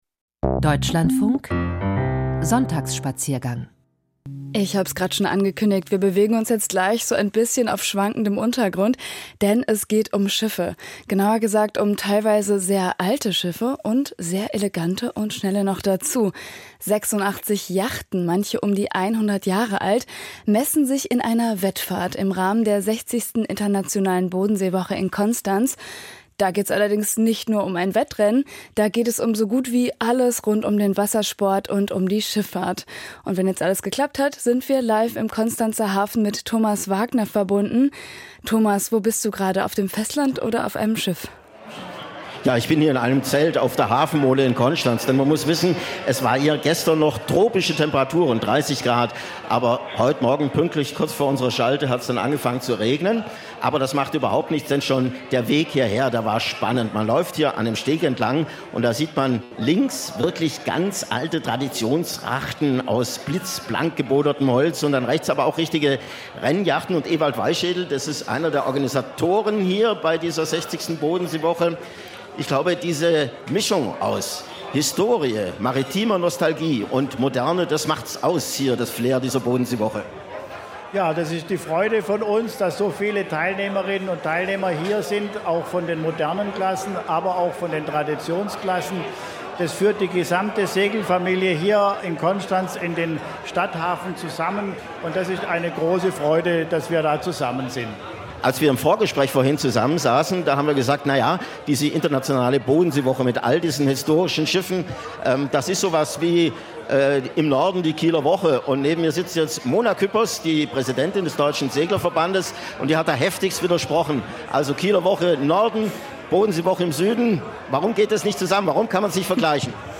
Livegespräch: Internationale Bodenseewoche Konstanz